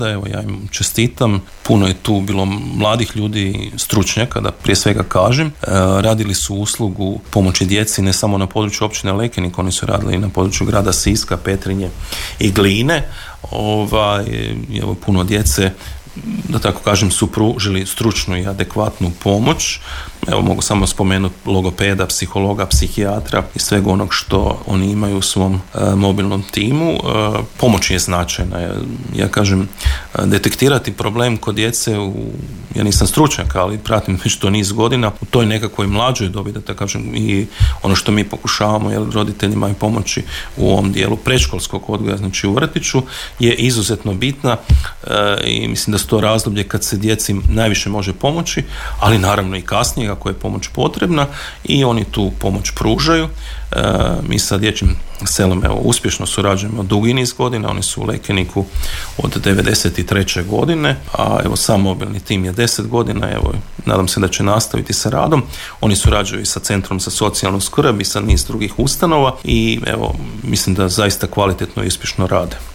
Više o tome, načelnik Općine Lekenik Ivica Perović